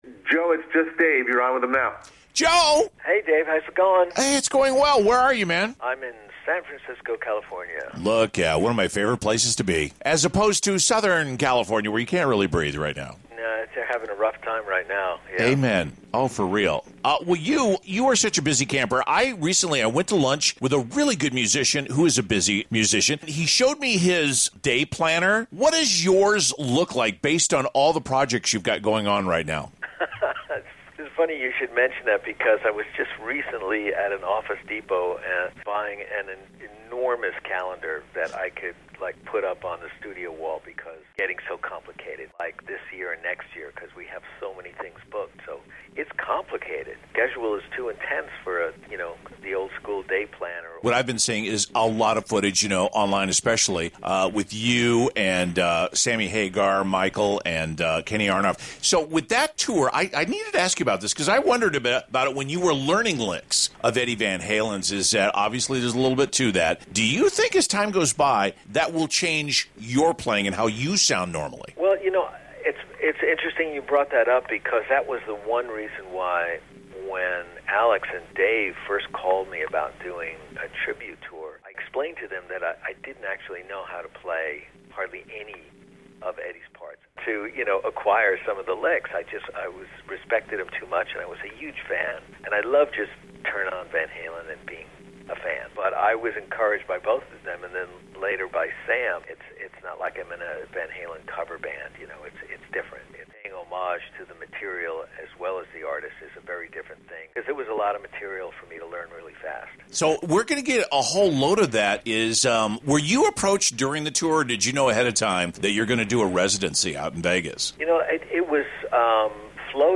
Guitar Virtuoso & 15-Time Grammy Award Nominee Releases New “Reunion Live” Collection with Steve Vai & Eric Johnson & Joins Sammy Hagar for ‘The Best of All Worlds 2025 Tour Vegas Residency’ Listen to my interview with Joe here….